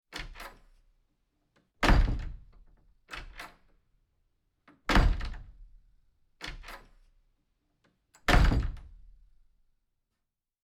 Тяжелая дверь: открывание, закрывание